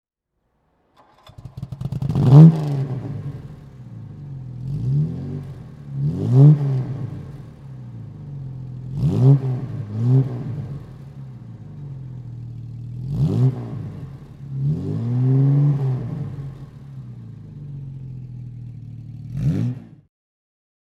Jaguar_Mk_2_1964.mp3